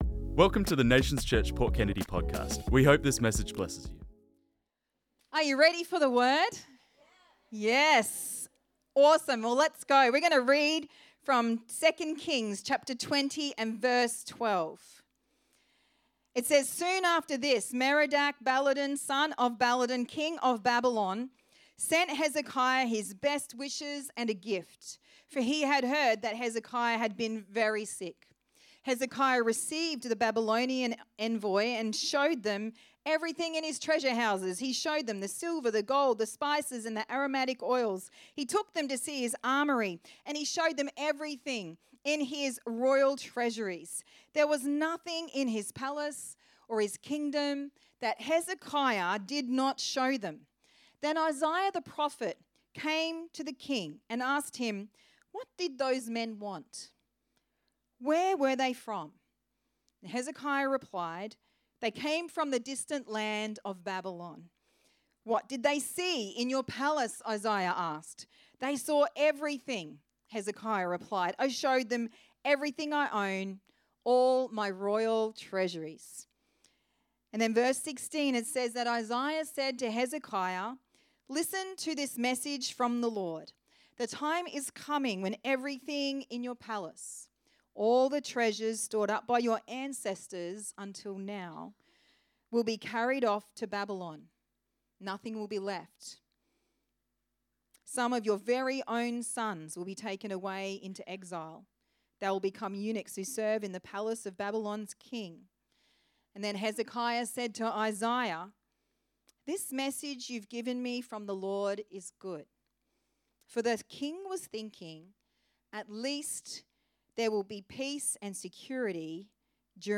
This message was preached on Sunday 22nd March 2026.